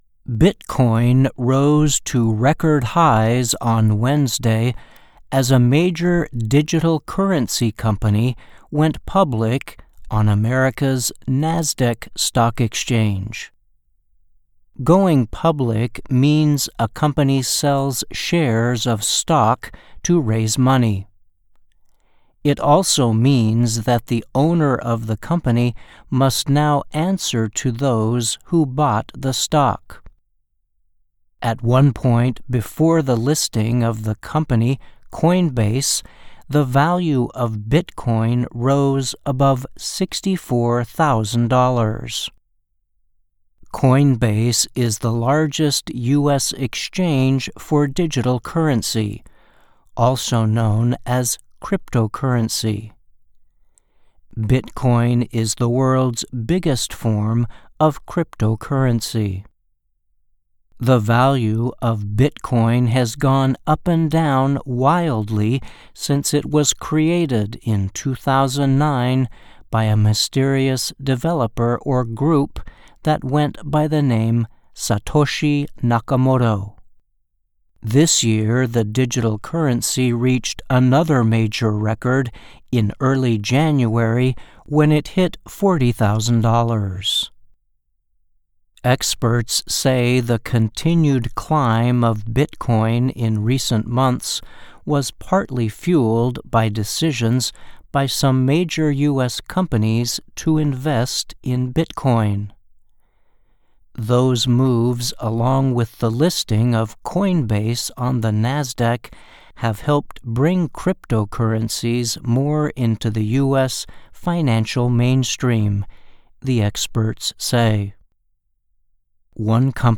慢速英语:随着数字货币公司上市 比特币创下历史新高